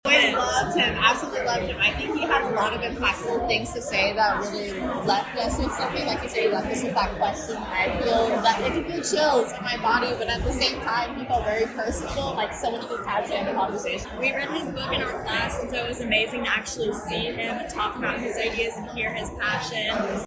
The internet icon and legendary actor provided a lecture at K-State Wednesday at McCain Auditorium.